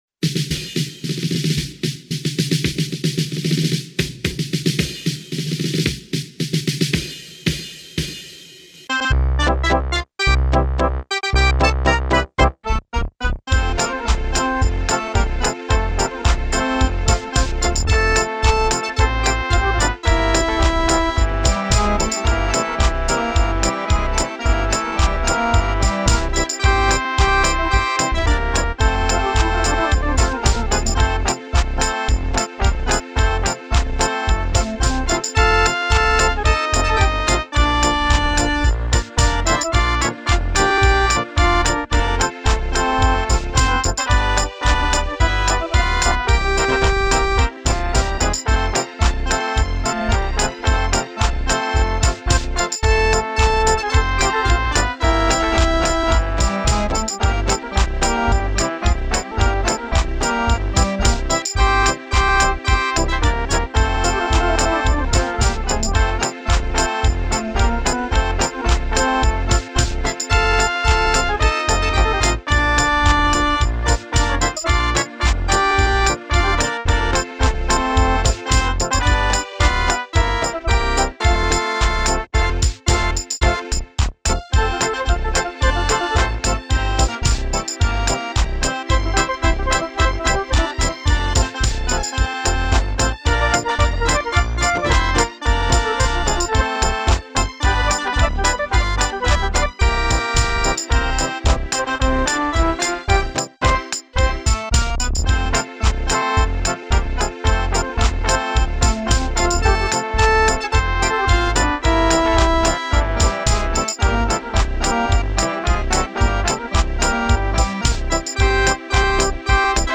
De carnavalsweek staat een schoolbreed thema centraal met een daarbij passend carnavalslied.
Carnavalslied 2025 Ontdek jouw talent